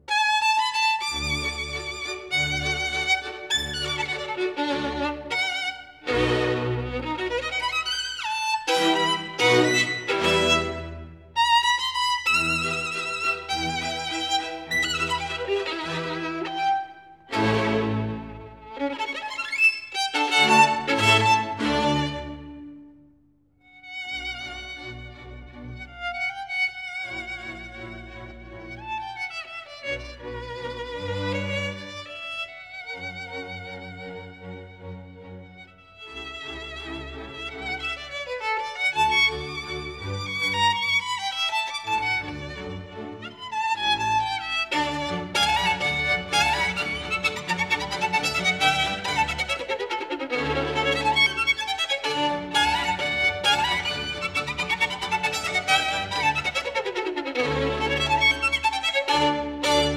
I also worked for some time on the filter for the violin music.